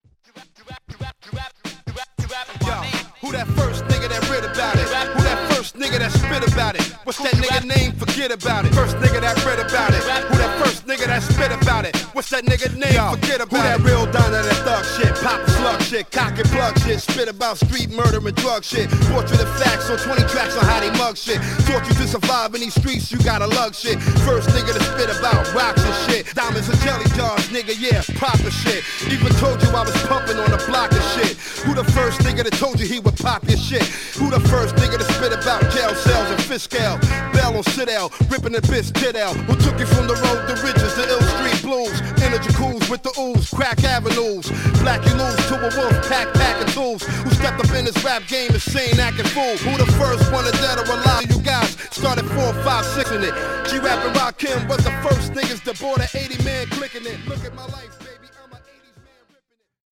ホーム ｜ HIP HOP
音質は悪くないです。